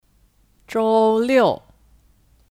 周六 Zhōu liù (Kata benda) : Hari SabtuAlternatif 星期六 Xīngqī liù dan 礼拜六 Lǐbài liù